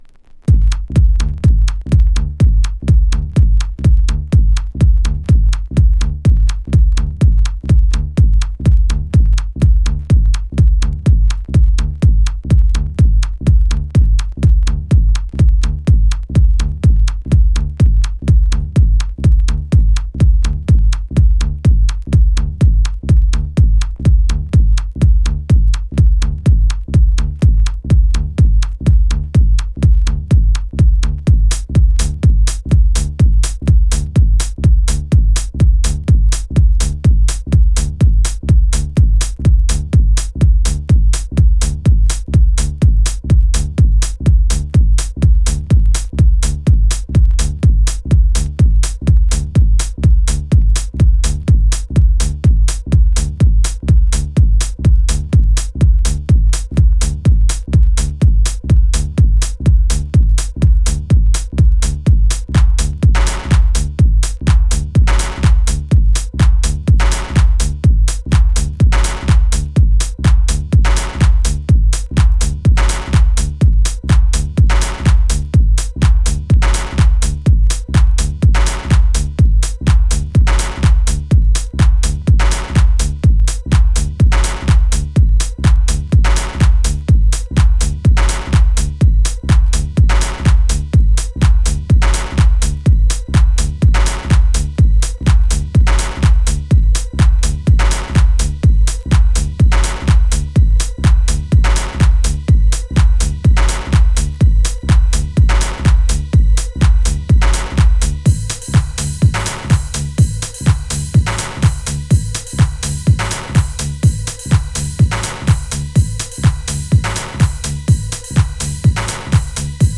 ファットな低音にバウンスするスネア、ダーティな味付けで展開するファンキーなエレクトリック・トラック2曲を収録。